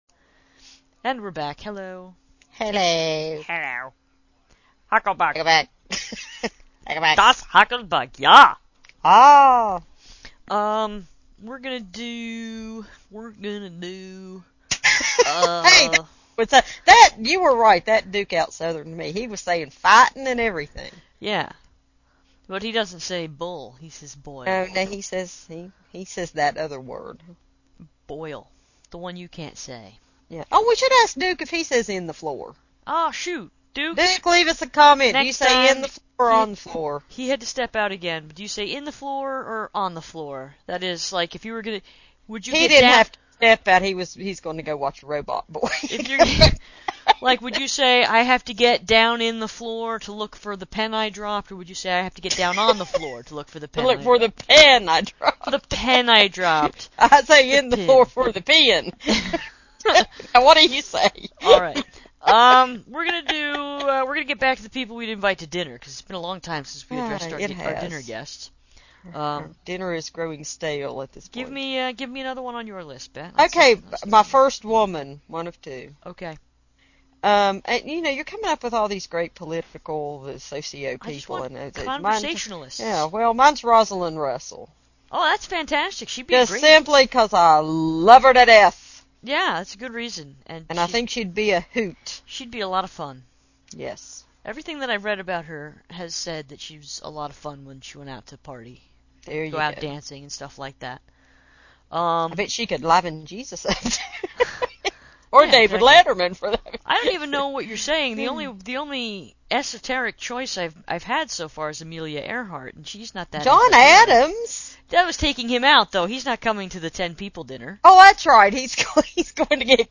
deep, deep southern accent